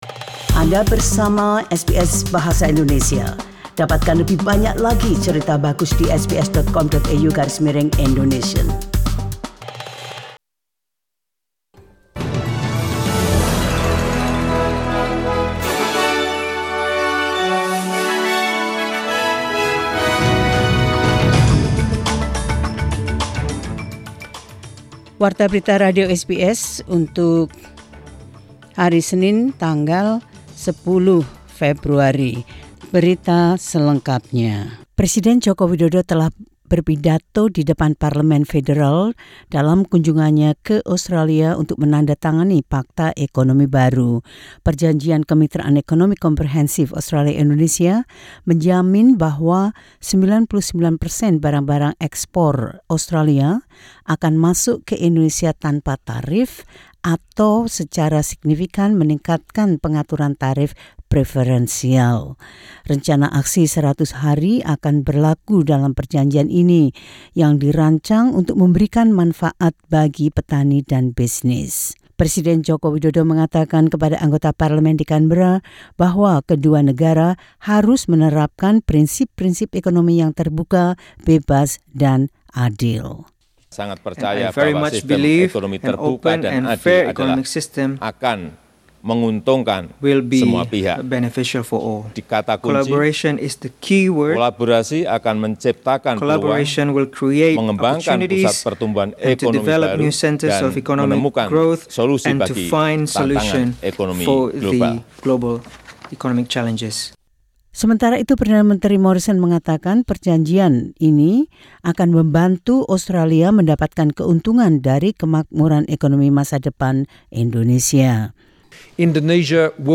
SBS Radio News in Indonesian 10 Feb 2020.